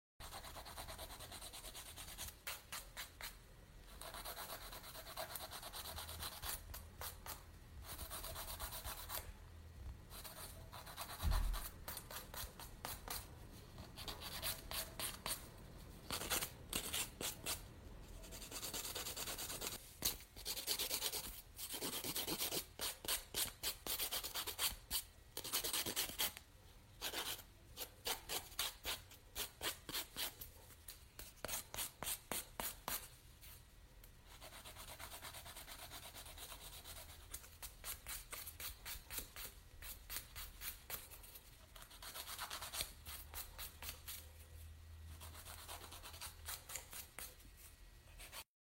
Tiếng Mài, Dũa Móng Tay, làm nail…
Thể loại: Tiếng động
Description: Tiếng mài, dũa móng tay, tiếng giũa nail lách cách, ken két vang lên đều đặn như một nhịp điệu quen thuộc trong tiệm làm nail. Âm thanh sắc, nhẹ, đôi khi rít lên tinh tế khi dũa chạm vào bề mặt móng, xen lẫn tiếng cọ xát, mài nhẵn, gọt giũa tỉ mỉ. Hiệu ứng âm thanh này thường được dùng trong video ASMR hoặc clip làm nail, mang lại cảm giác thư giãn, chân thực, mô phỏng rõ nét không gian làm đẹp chuyên nghiệp và tỉ mỉ của thợ nail.
tieng-mai-dua-mong-tay-lam-nail-www_tiengdong_com.mp3